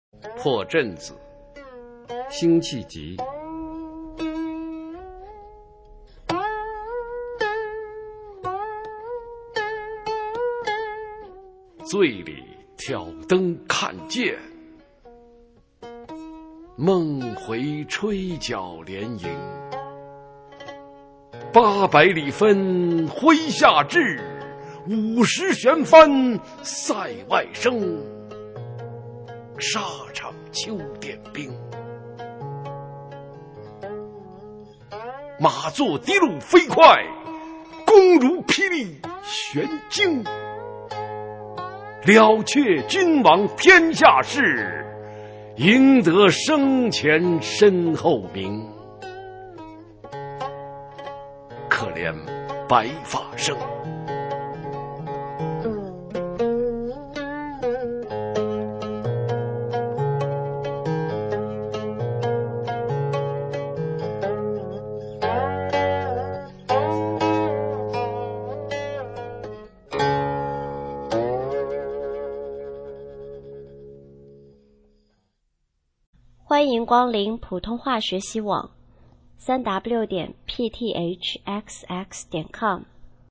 普通话美声欣赏-破阵子·为陈同甫赋壮词以寄之